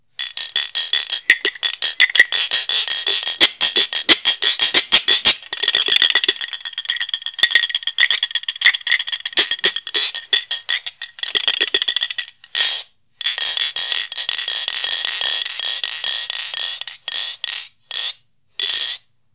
4.2.2.1.CẶP KÈ hay SÊNH SỨA
ặp Kè tiếng trong, dòn, vui tươi, có những tiếng rung rất độc đáo.